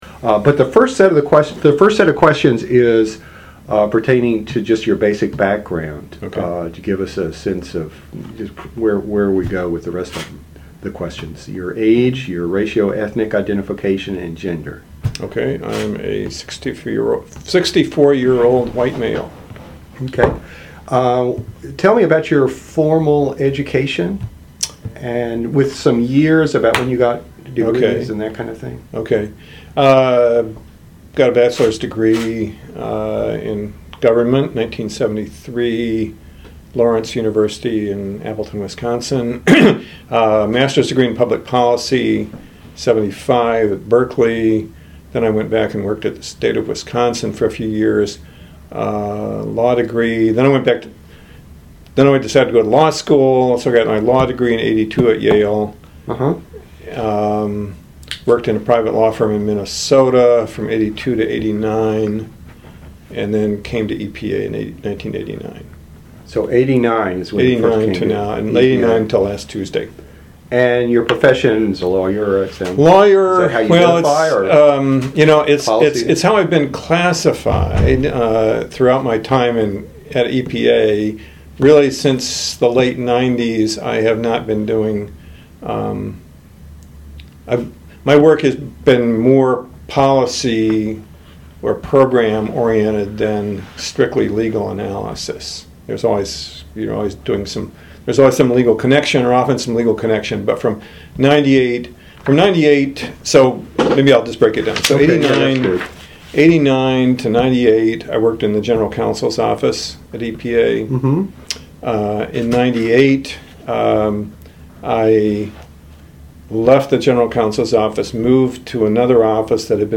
Environmental Data & Governance Initiative Oral History Project